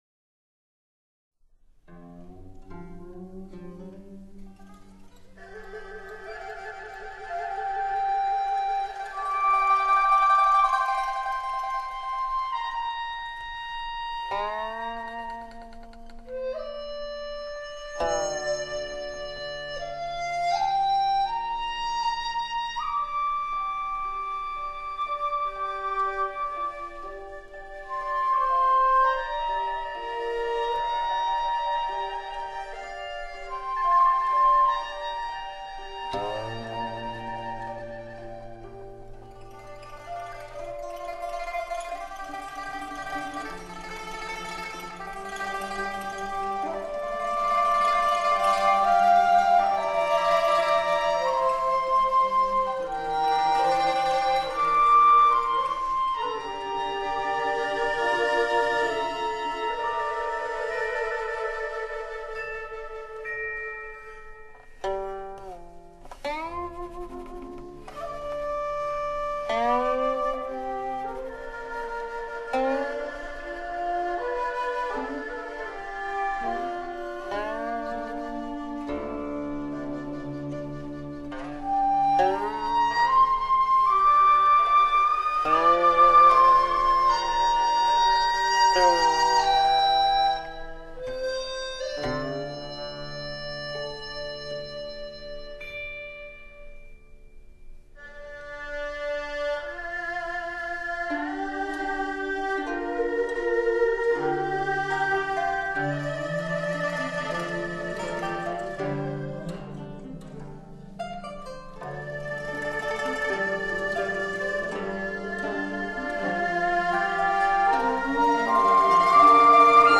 古琴